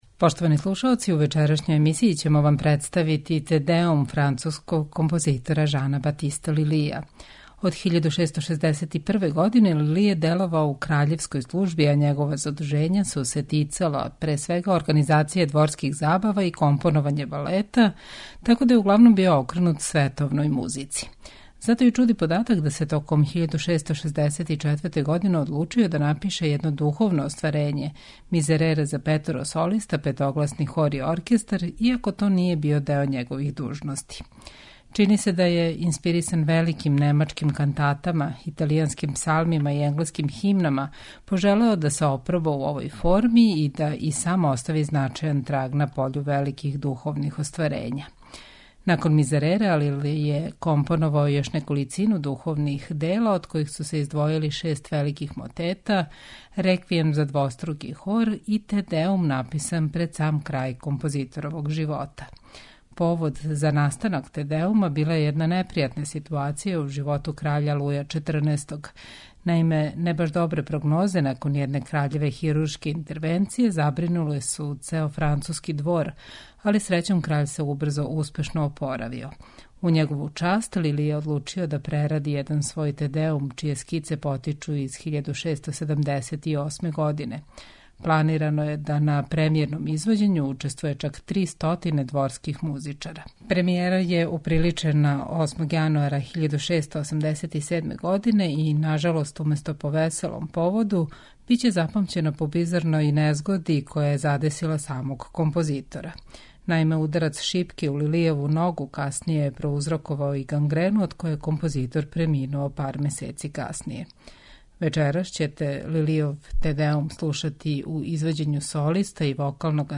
медитативне и духовне композиције